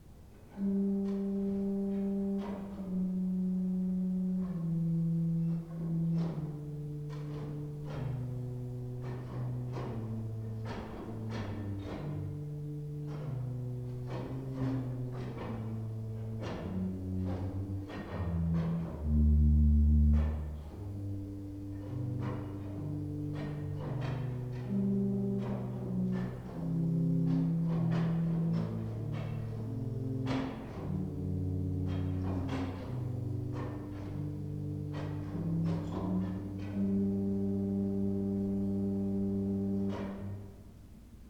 1787 Tannenberg Organ
To listen to a demonstration of the Octav Bass 8', click
Demonstration_Octav_Bass_8F.wav